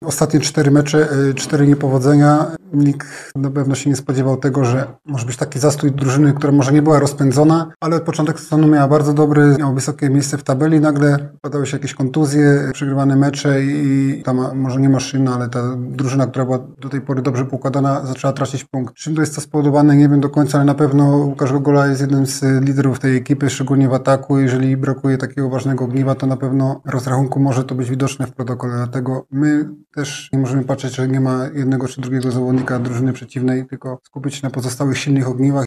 Drugi trener Industrii Krzysztof Lijewski podkreśla, że nie ma mowy o lekceważeniu przeciwnika.